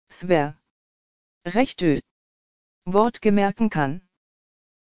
abgehackt an.